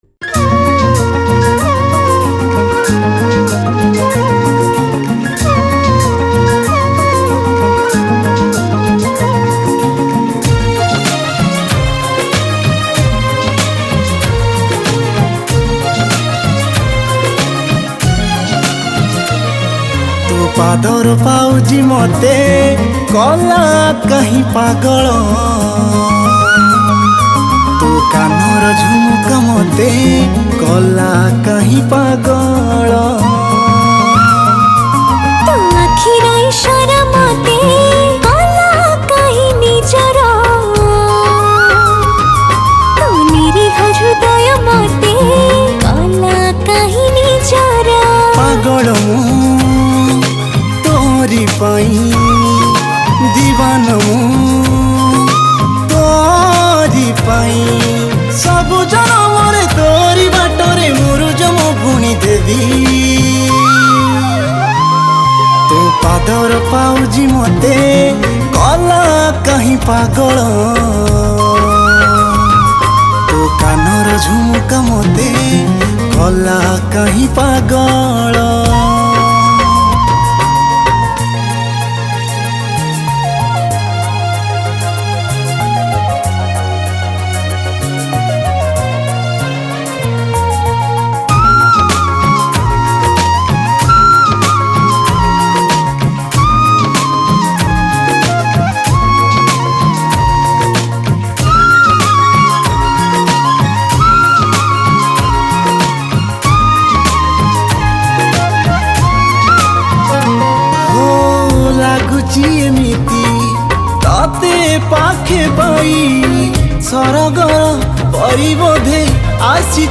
Song Type :Romantic Song